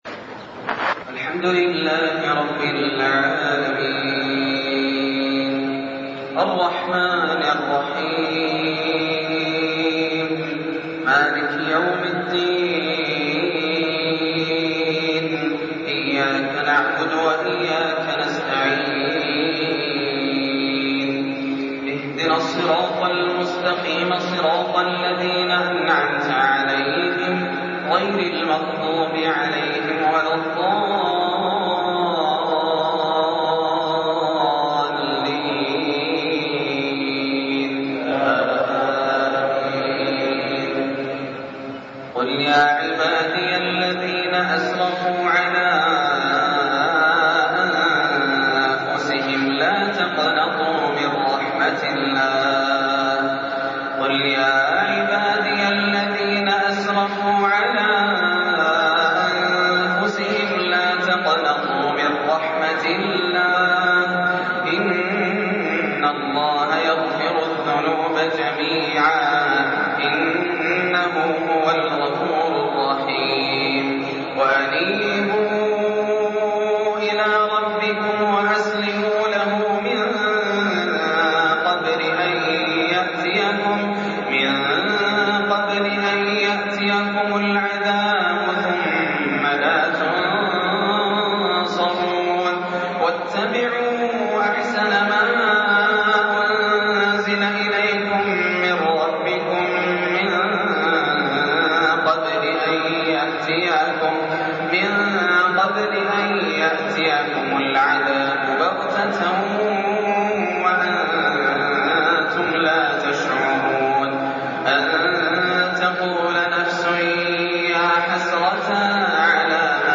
(قل يا عبادي الذين أسرفوا على أنفسهم) أواخر سورة الزمر - عشائية رااائعة > عام 1424 > الفروض - تلاوات ياسر الدوسري